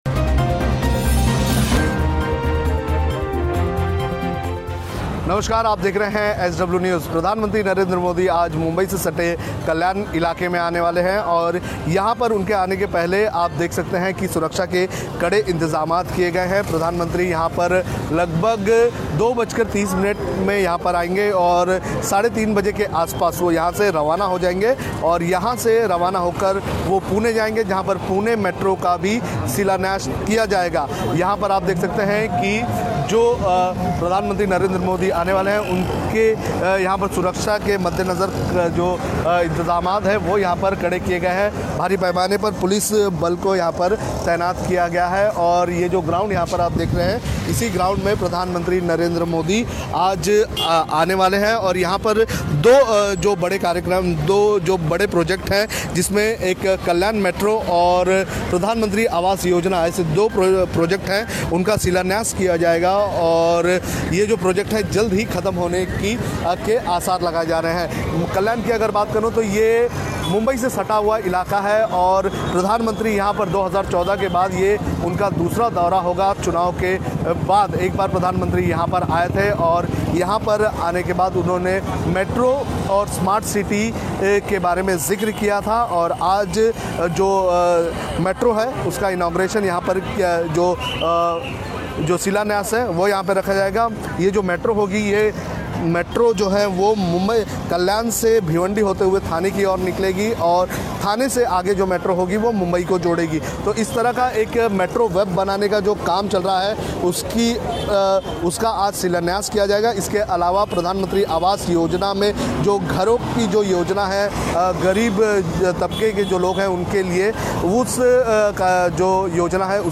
न्यूज़ रिपोर्ट - News Report Hindi / प्रधानमंत्री नरेंद्र मोदी आज कल्याण में करेंगे मेट्रो प्रोजेक्ट का शिलान्यास